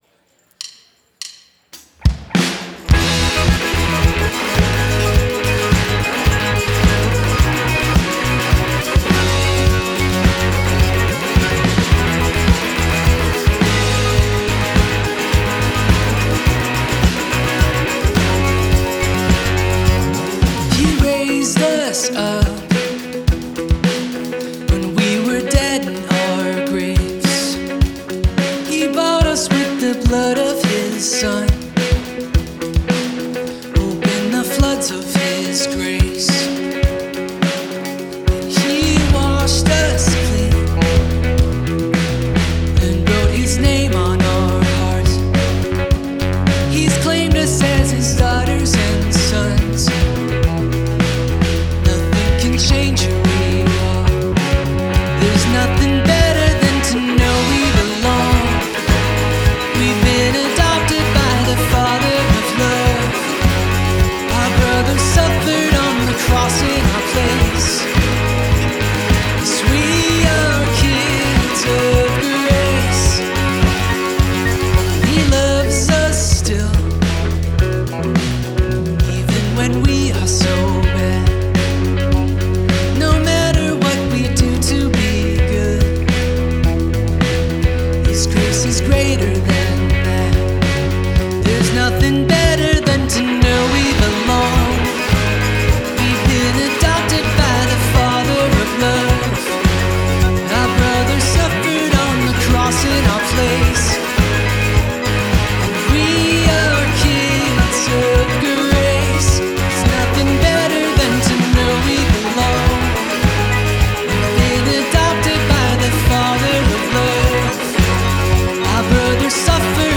Here are some of the musical adventures of the worship team that I've mixed in post-production